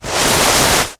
Звуки санок